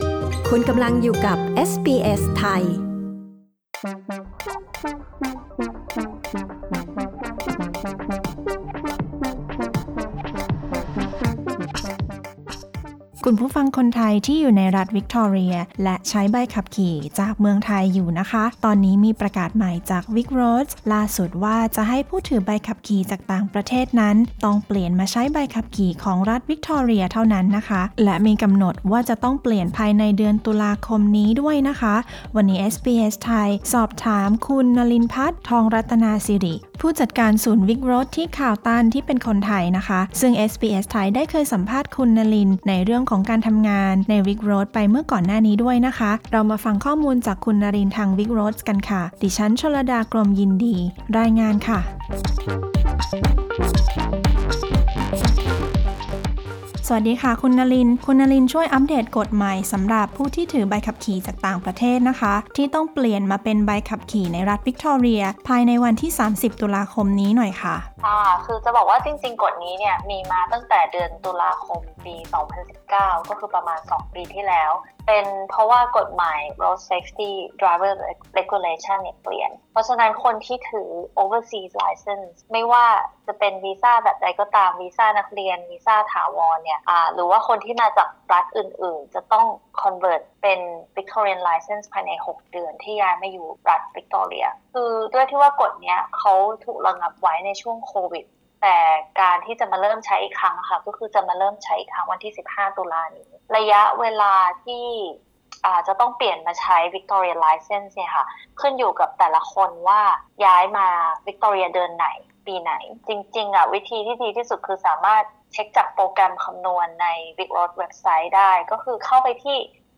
ให้สัมภาษณ์เรื่องนี้ พร้อมตอบคำถามจากชุมชนไทย
intv_vicroads_overseas_licence.mp3